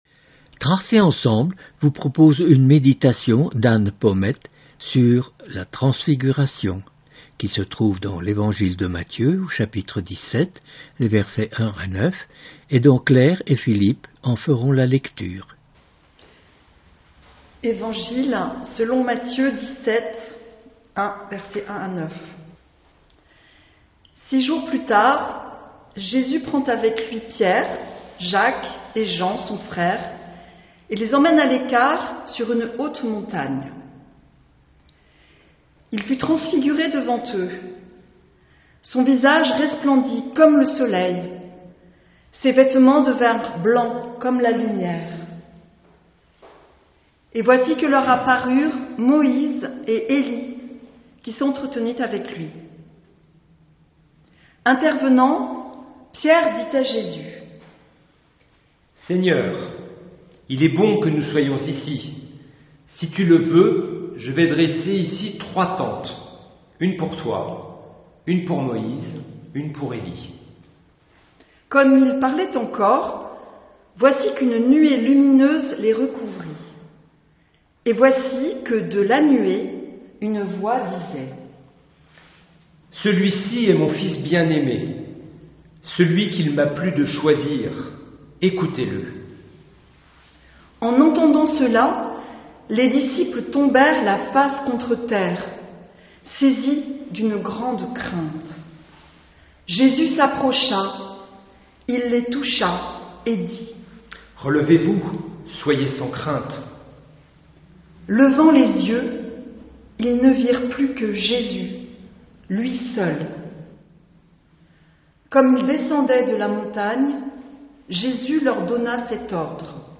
Culte